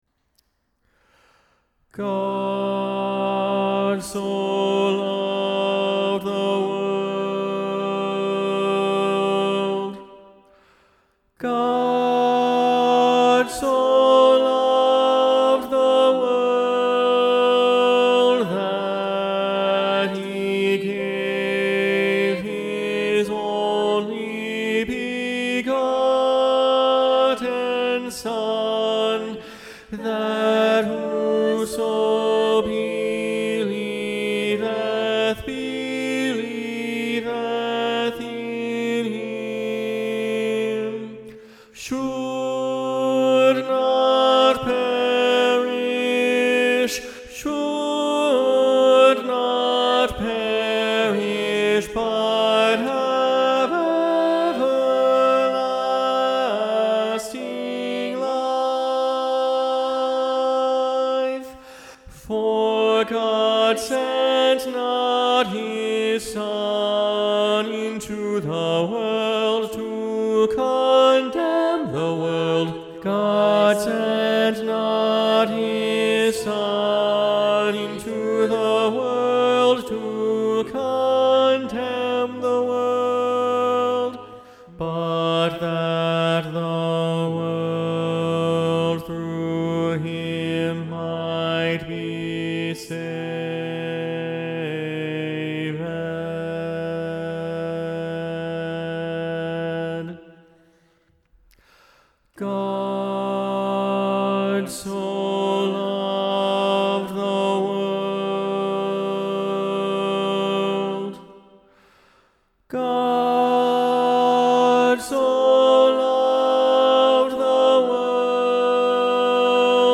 God so Loved the World SATB – Tenor Predominant – John StainerDownload